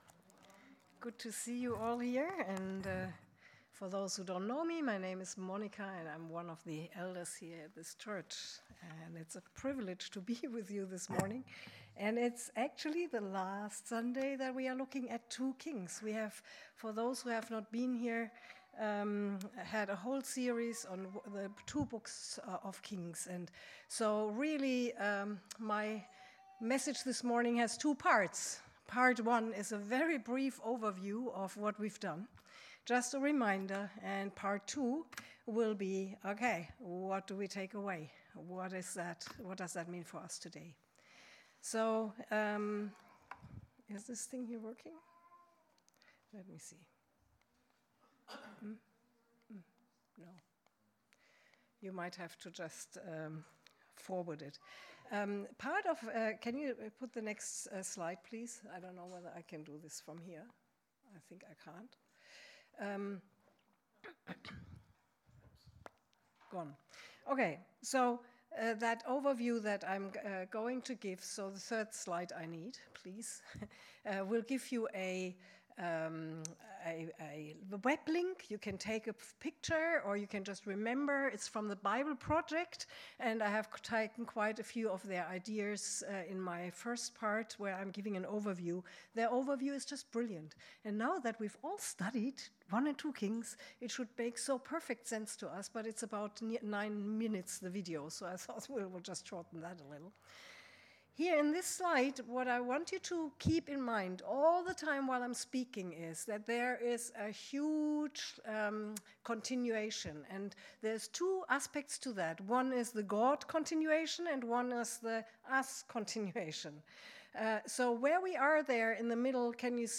Passage: 2 Kings - Chapter 23: 31 - Chapter 25 Service Type: Sunday 10 am